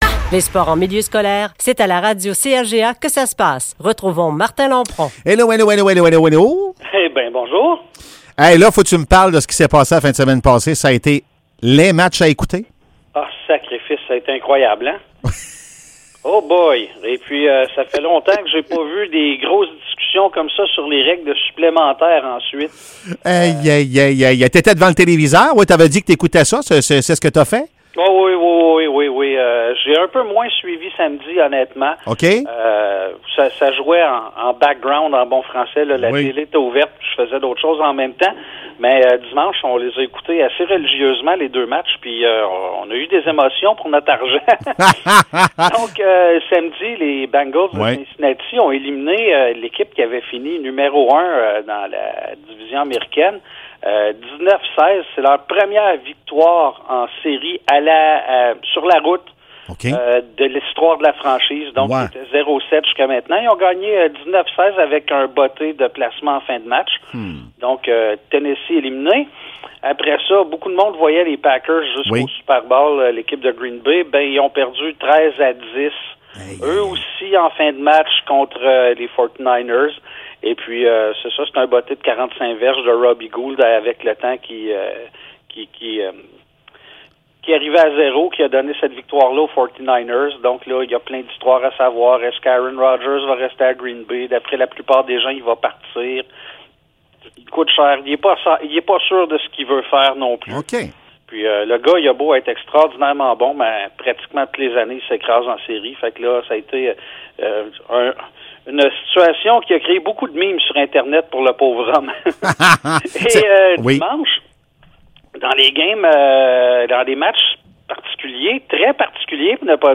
Chronique sports